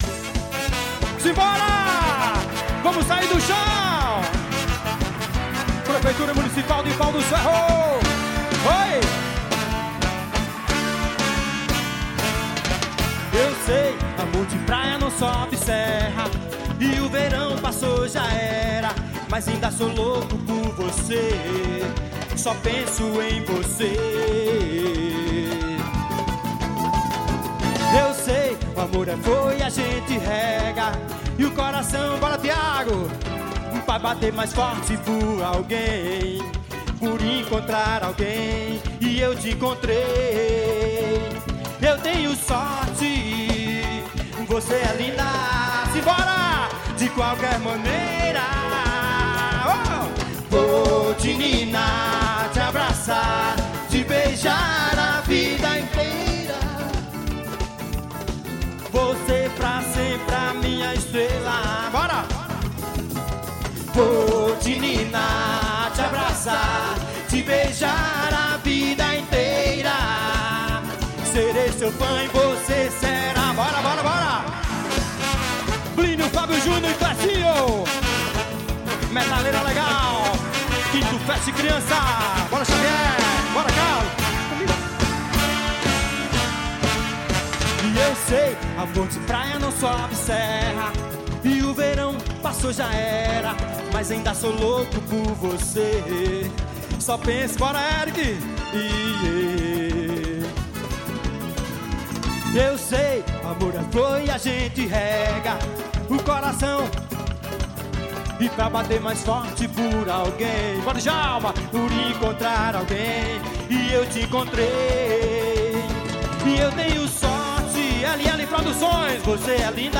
ao vivo Fest Criança 2009.